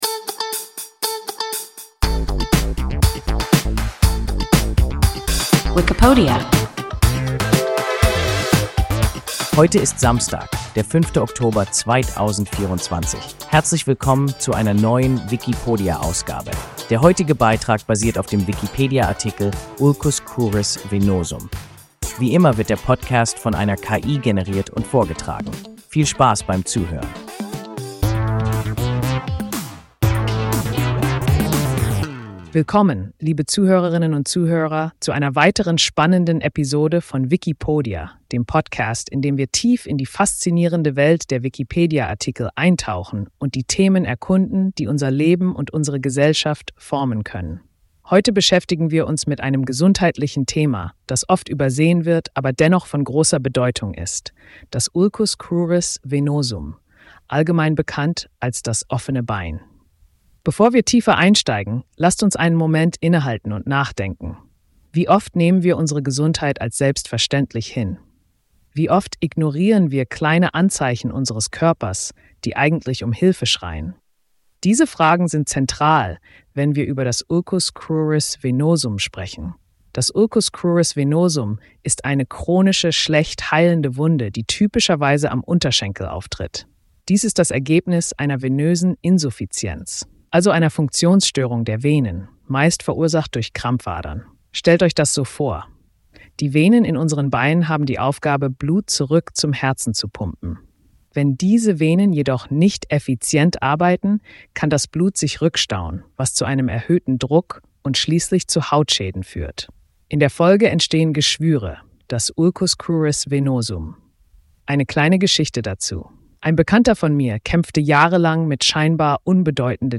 Ulcus cruris venosum – WIKIPODIA – ein KI Podcast